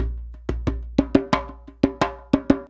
089DJEMB10.wav